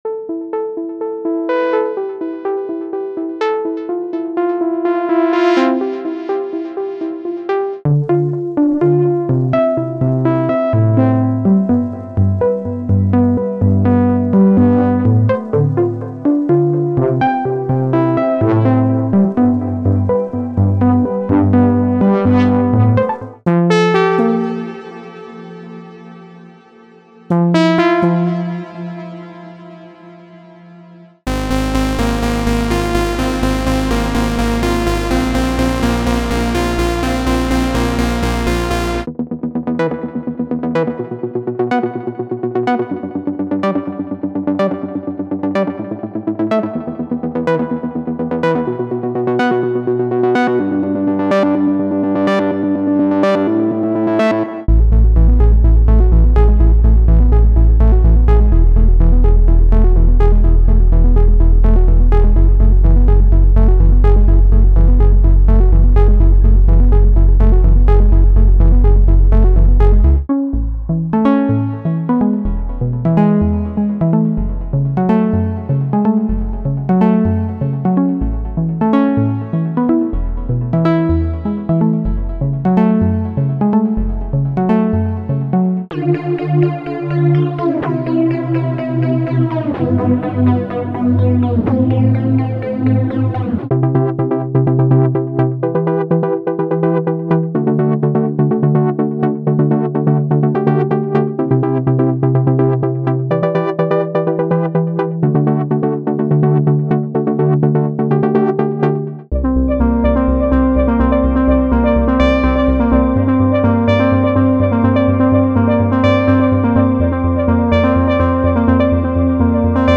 brings you 100+ deep, emotional Serum 2 presets
delivers 100+ Serum 2 presets designed for deep, emotive, and modern progressive house inspired by artists like Ben Böhmer
•100+ Serum 2 Presets – Bass, Leads, Plucks, Arps, Atmos